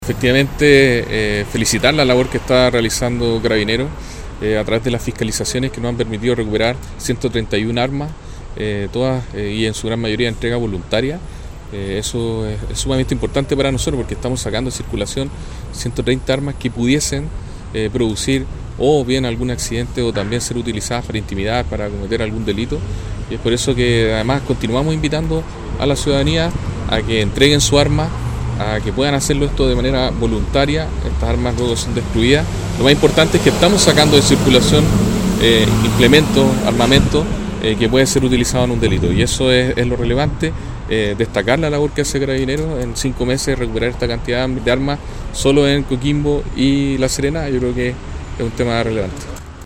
ENTREGA-DE-ARMAS-Delegado-Presidencial-Galo-Luna.mp3